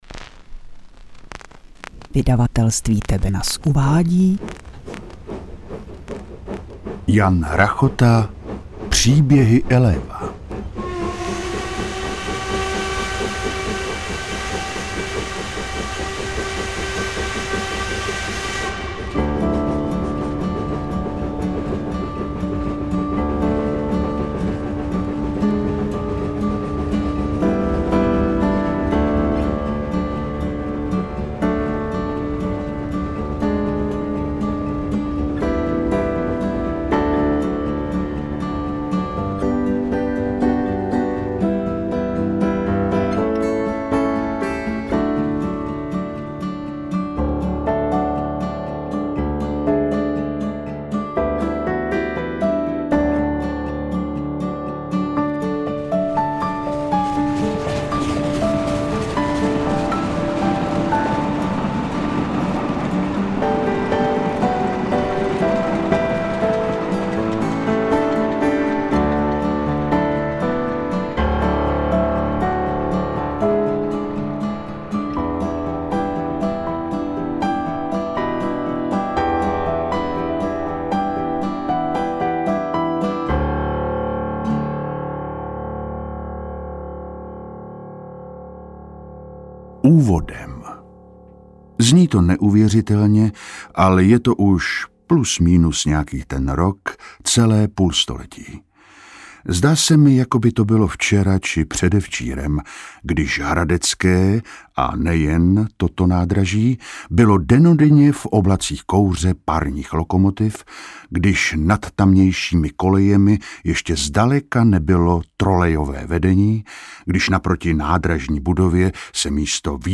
Interpret:  Martin Zahálka
AudioKniha ke stažení, 10 x mp3, délka 3 hod. 56 min., velikost 395,0 MB, česky